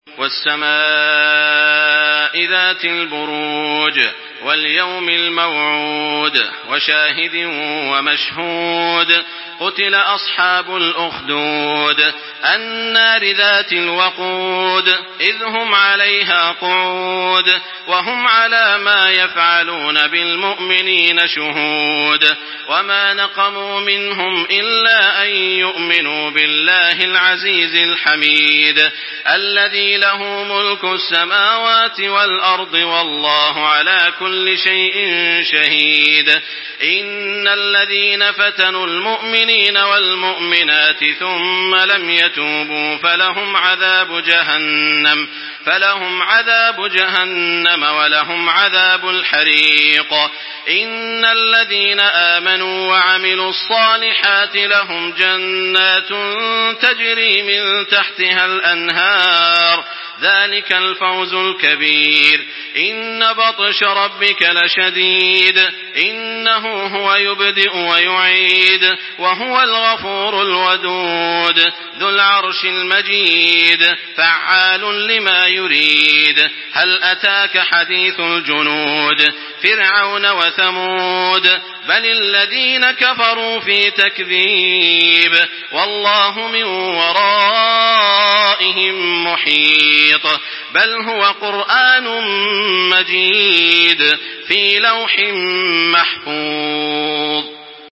تحميل سورة البروج بصوت تراويح الحرم المكي 1425
مرتل